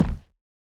added stepping sounds
BootsLinoleum_03.wav